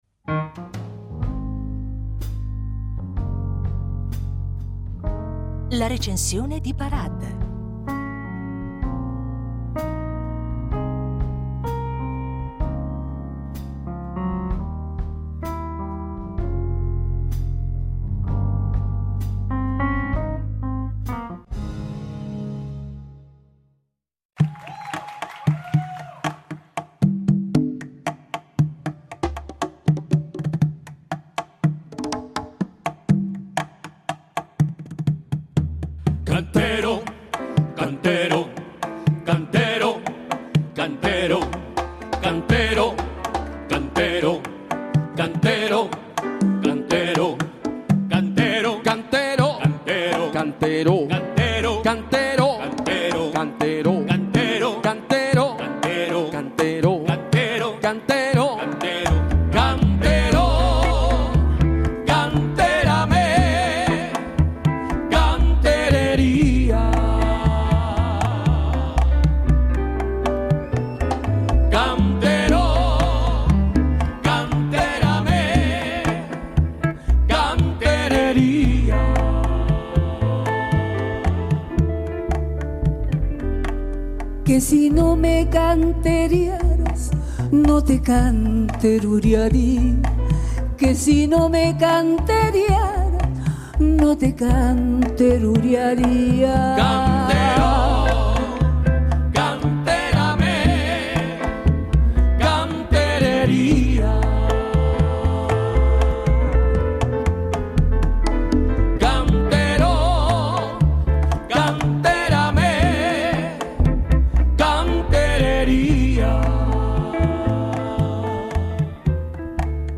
Un’occasione per ritrovare la sua magnifica voce